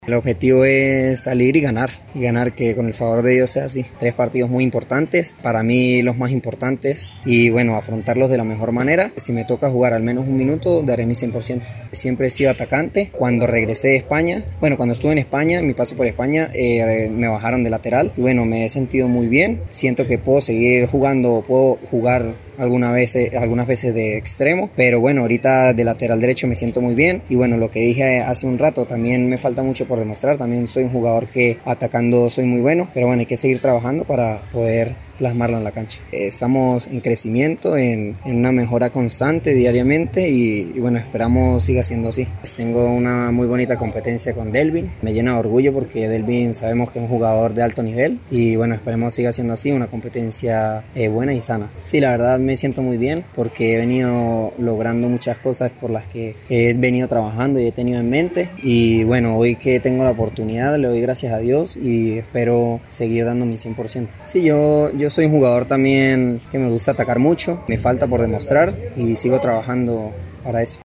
En el encuentro con los medios de comunicación realizado este miércoles en la Cancha Alterna